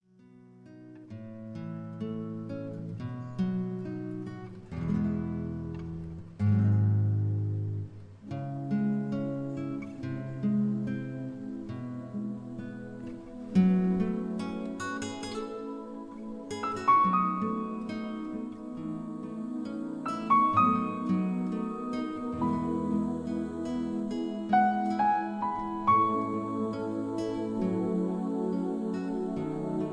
(Key-E, Tono de E)
mp3 backing tracks